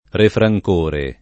Refrancore [ refra j k 1 re ]